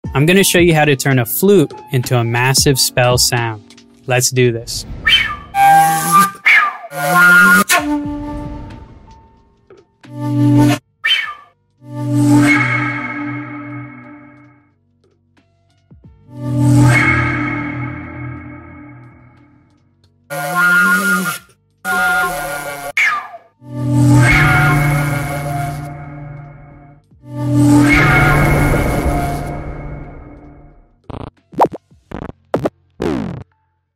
Turning a flute into a sound effects free download
Turning a flute into a video game sound spell sound!